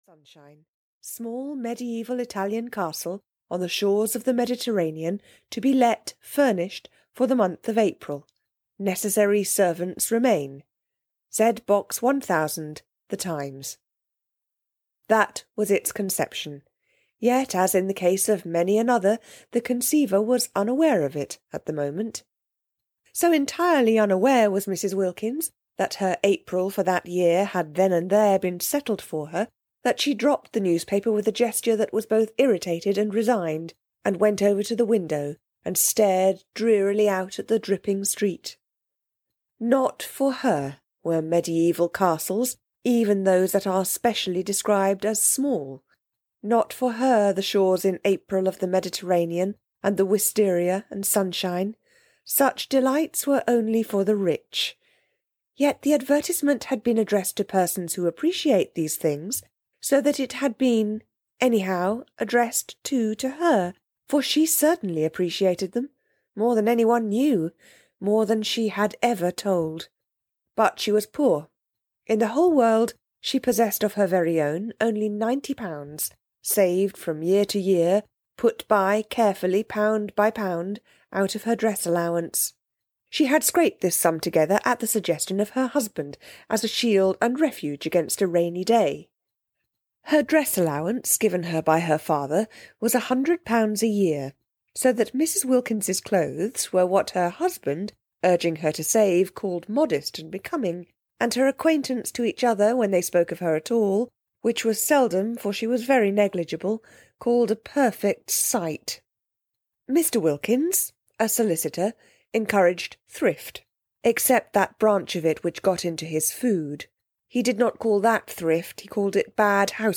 The Enchanted April (EN) audiokniha
Ukázka z knihy